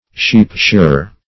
sheep-shearer.mp3